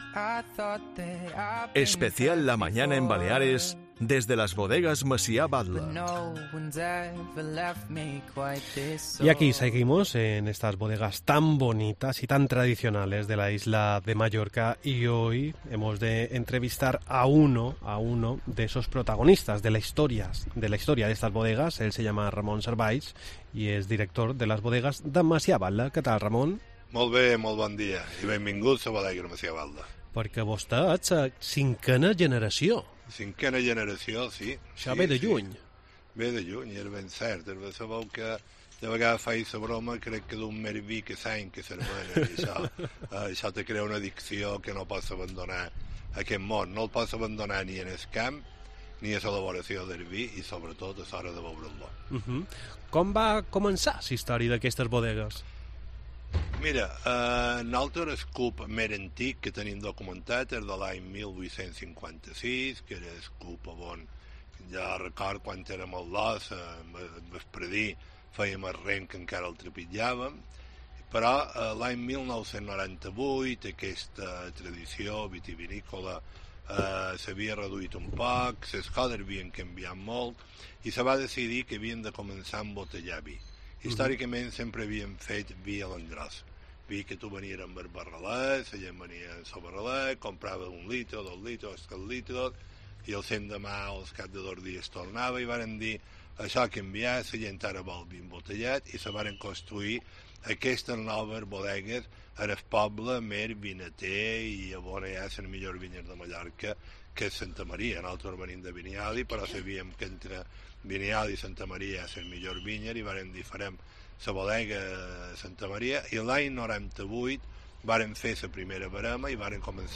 A lo largo de la entrevista nos recuerda cómo él solía vendimiar, disfrutar y correr por los viñedos junto con su familia y amigos